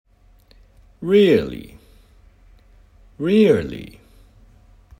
大きな驚きの時と、小さな驚きの時でイントネーションを変えることで同じ単語を使いまわすことができます。
大きな驚き：「まじで！？」「うそ！？」語尾を上げます。
小さな驚き：「へー」「そうなんだ」語尾を下げます。
最初と最後は大きな驚きの「Really」で二番目は小さな驚きの「Really」ですね。